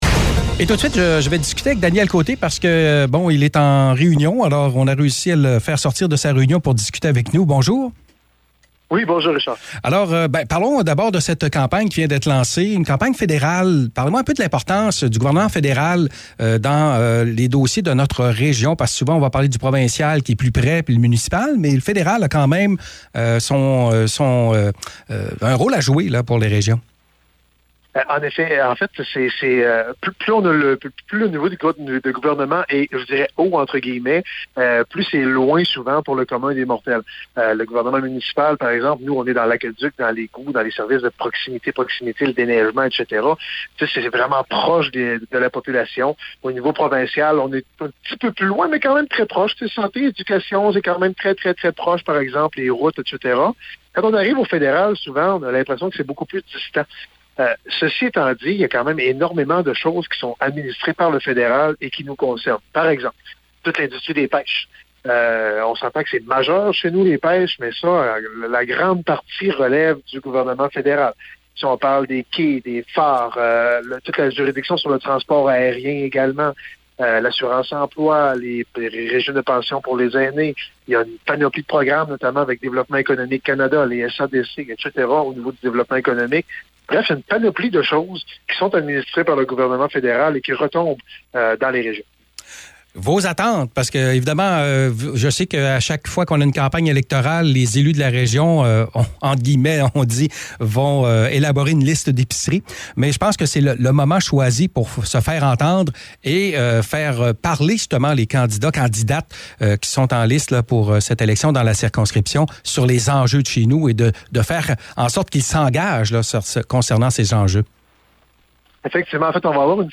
Entrevue avec le maire de Gaspé et préfet de la MRC de La Côte-de-Gaspé, Daniel Côté: